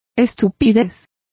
Complete with pronunciation of the translation of stupidity.